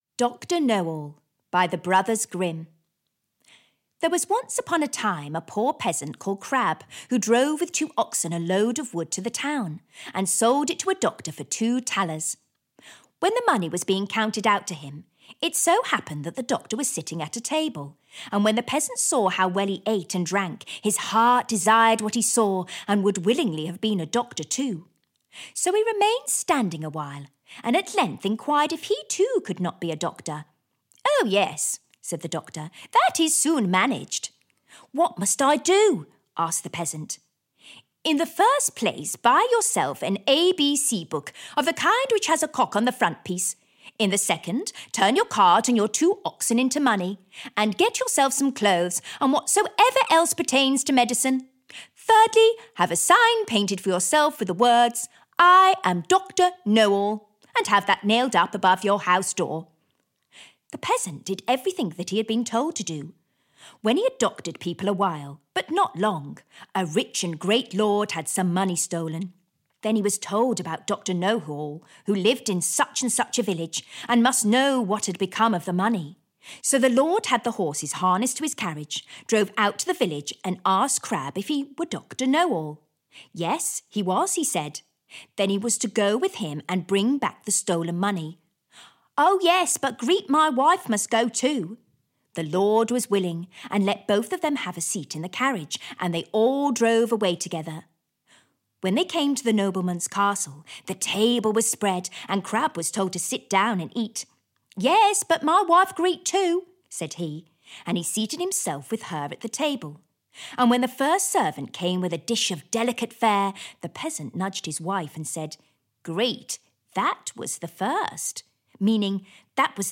Once Upon a Time: Bedtime Stories for Children (EN) audiokniha
Ukázka z knihy